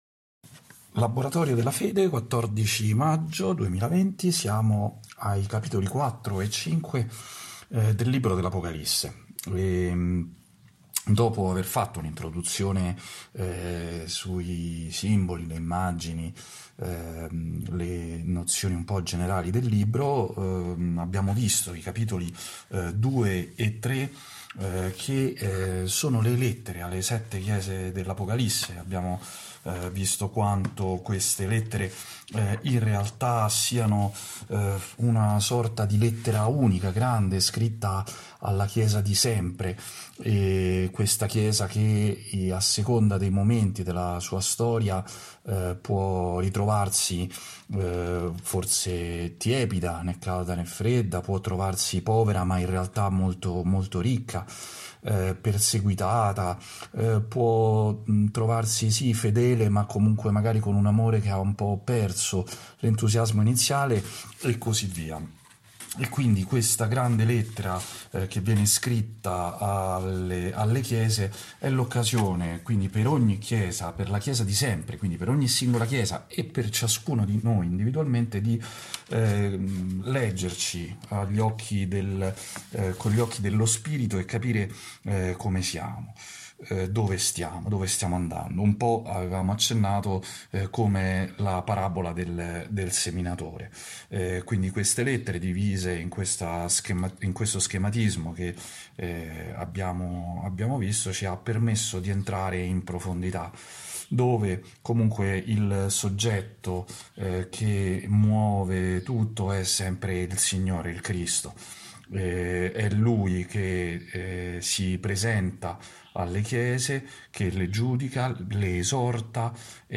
Tipo: Audio Catechesi